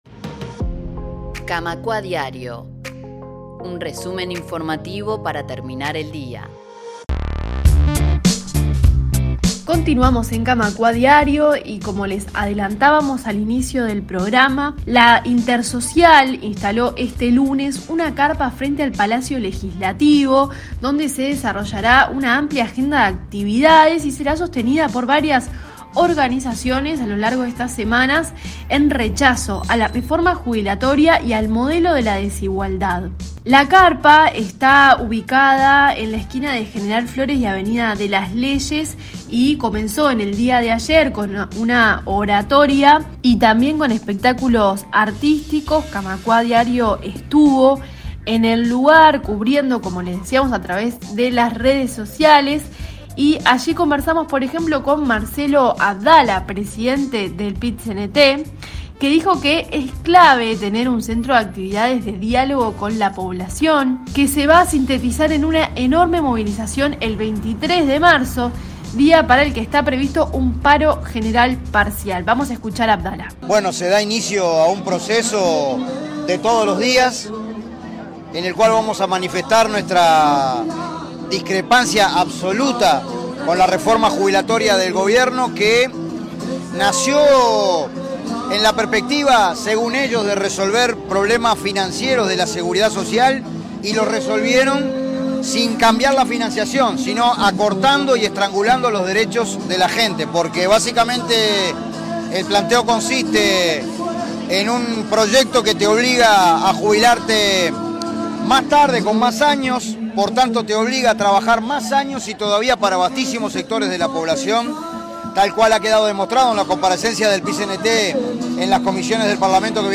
Radio Camacuá estuvo presente en el lugar y en esta nota compartimos algunas voces que compartieron sus perspectivas desde el lanzamiento de este espacio.